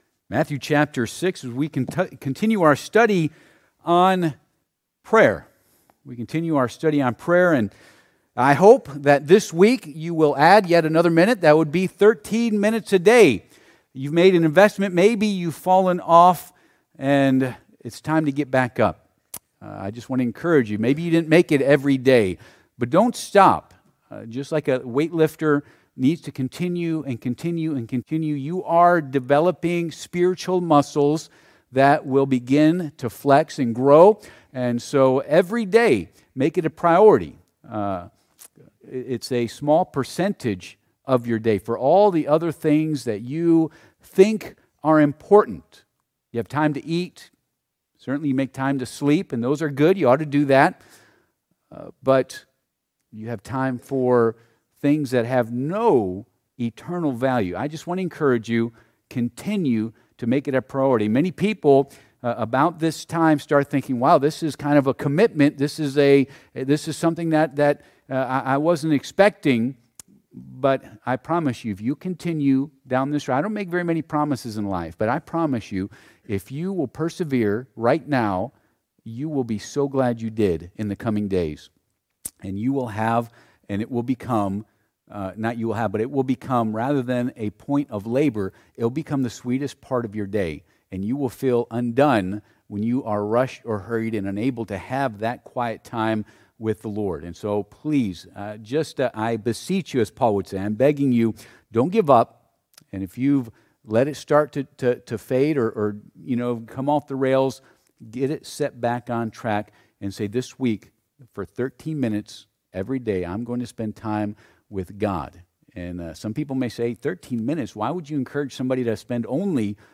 Passage: Matthew 6 Service Type: Sunday PM « Thou Shalt Not Commit Adultery The Kings of Israel and Judah